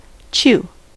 chew: Wikimedia Commons US English Pronunciations
En-us-chew.WAV